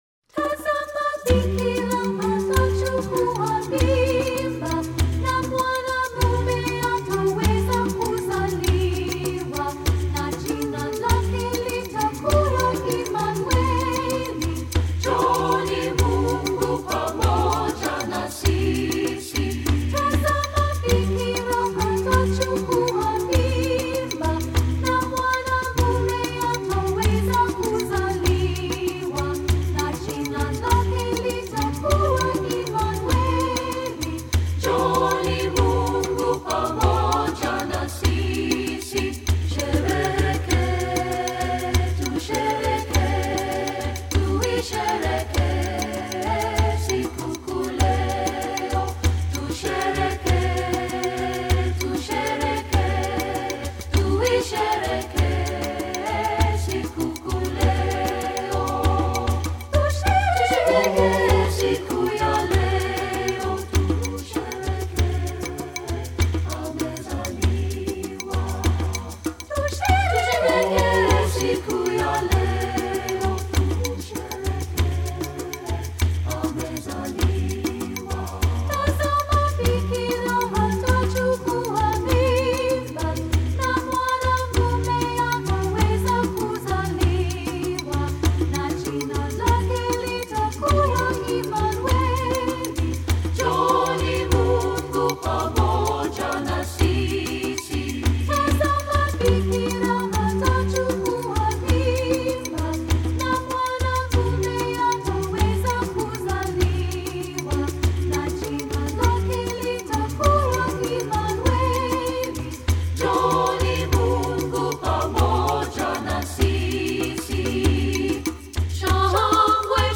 Composer: Kenyan Folk Song
Voicing: SAB a cappella